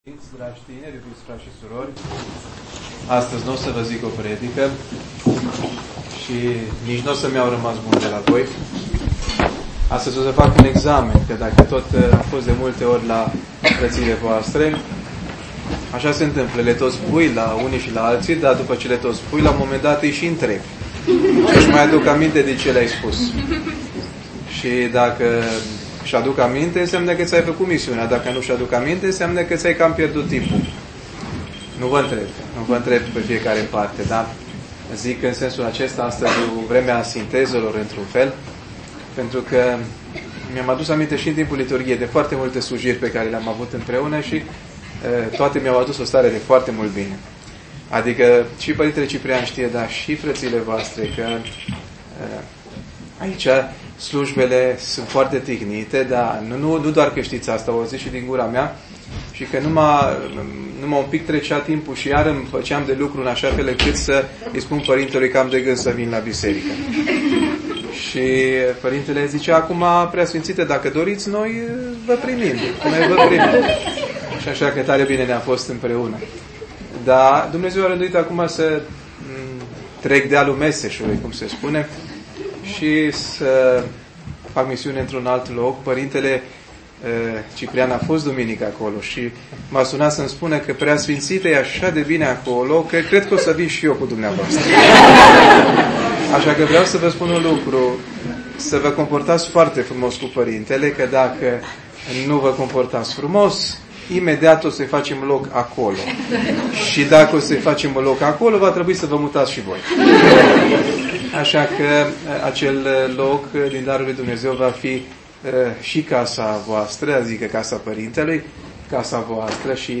Cuvânt
Cuvânt PS Benedict Bistrițeanul 2025-04-01 la Liturghia Darurilor mai înainte sfințite Biserica, școală a mucenicilor.